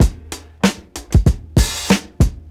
• 95 Bpm 2000s Breakbeat B Key.wav
Free breakbeat - kick tuned to the B note. Loudest frequency: 1204Hz